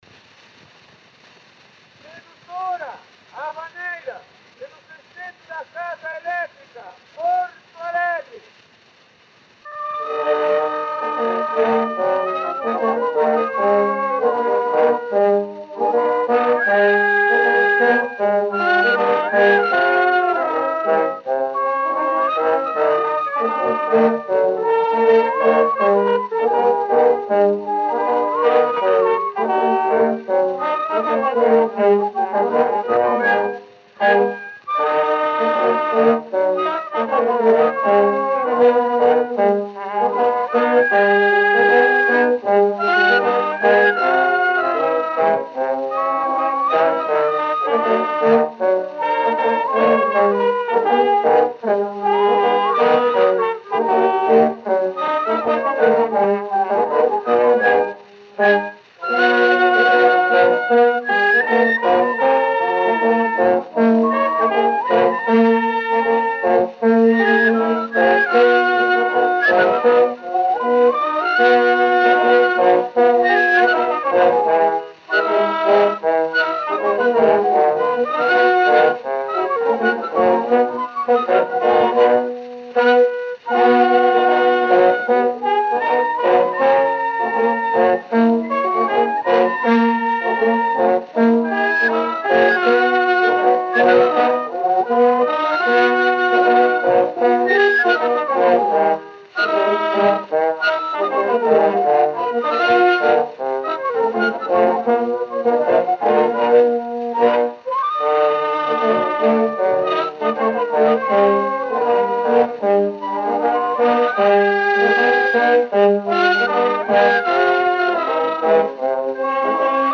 O gênero musical foi descrito como "Havaneira".